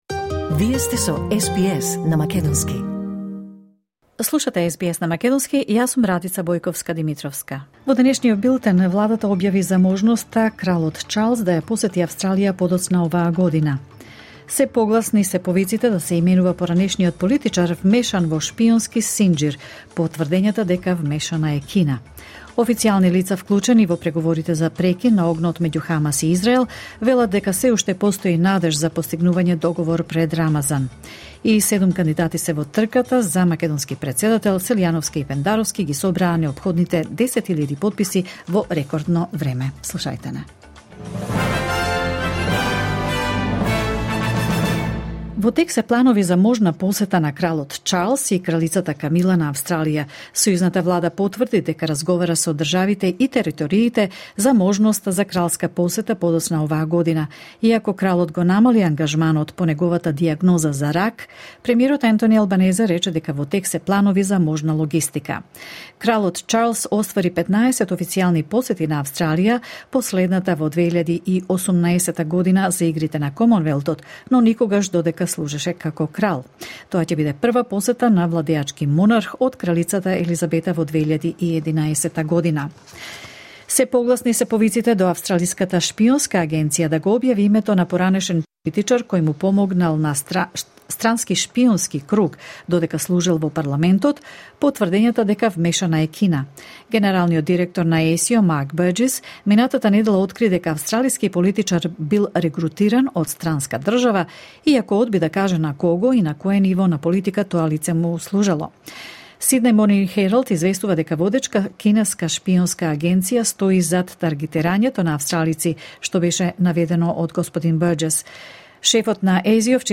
SBS News in Macedonian 4 March 2024